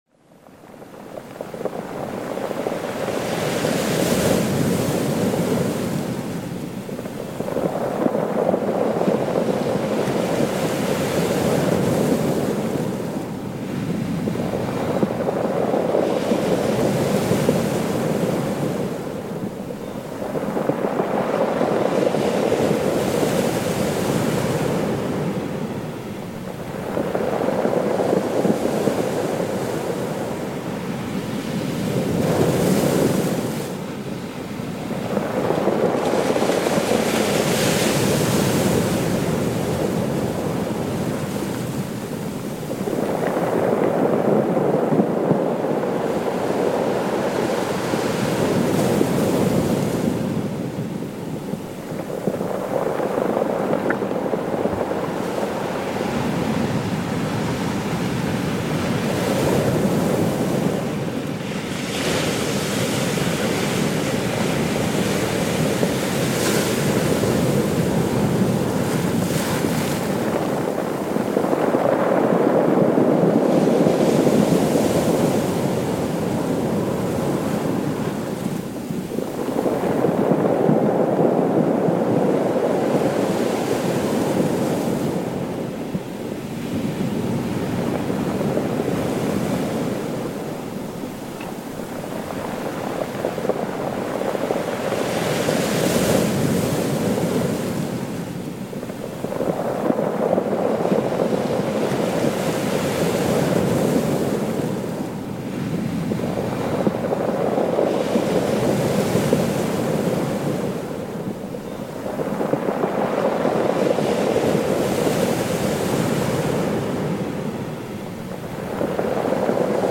HARMONIE-BOOSTER: Tiefenwellen-Therapie mit Meeresrauschen